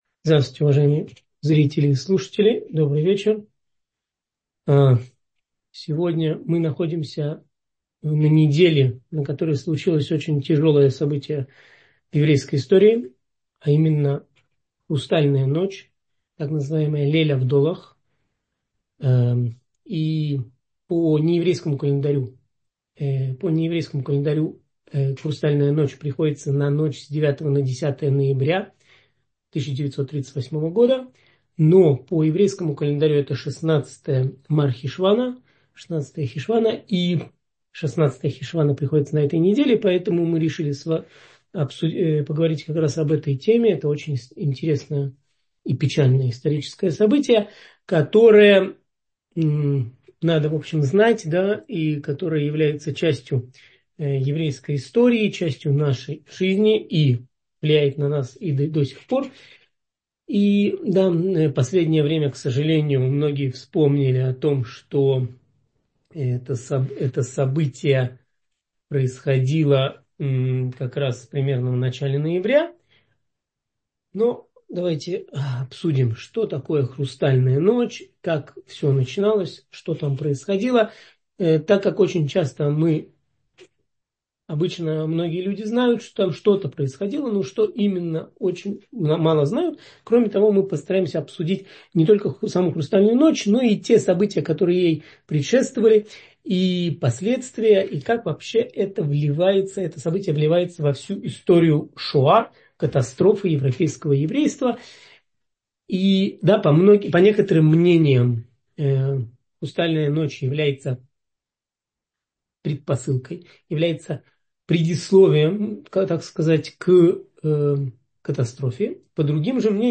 16 хешвана – Хрустальная ночь — слушать лекции раввинов онлайн | Еврейские аудиоуроки по теме «Проблемы и вопросы» на Толдот.ру